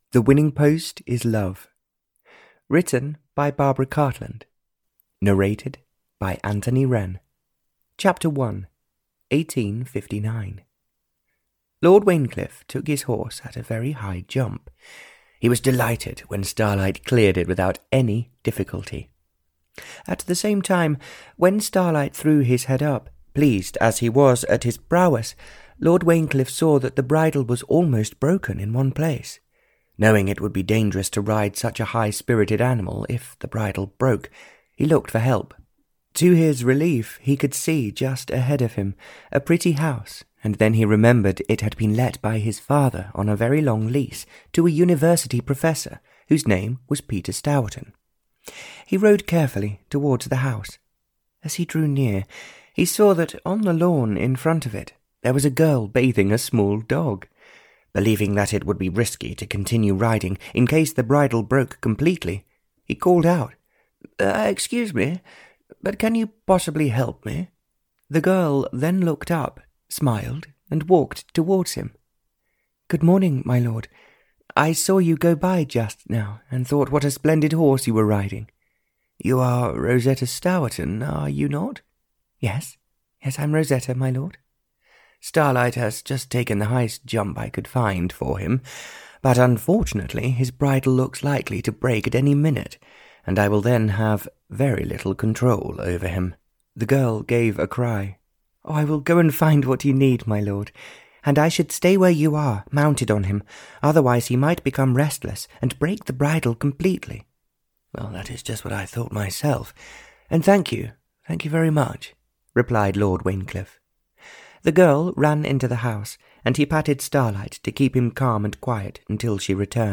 Audio knihaThe Winning Post is Love (Barbara Cartland's Pink Collection 91) (EN)
Ukázka z knihy